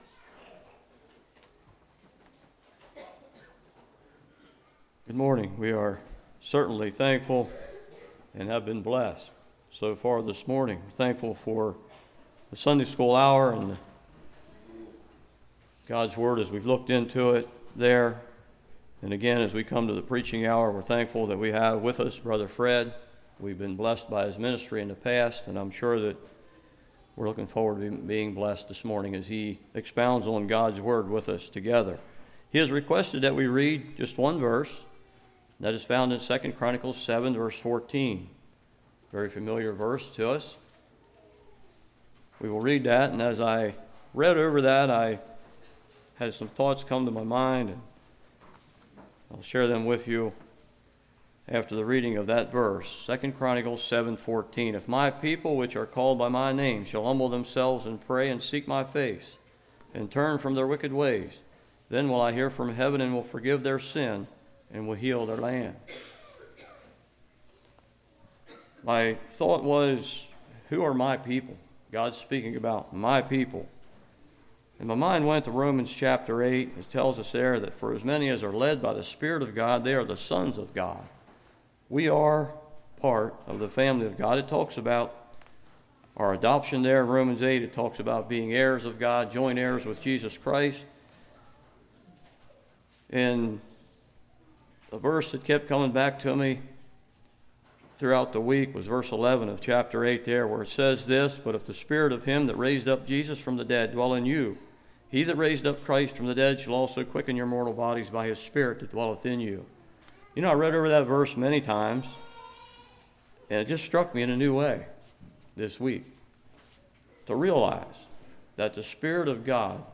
2 Chronicles 7:14 Service Type: Morning How Far Have I Wondered?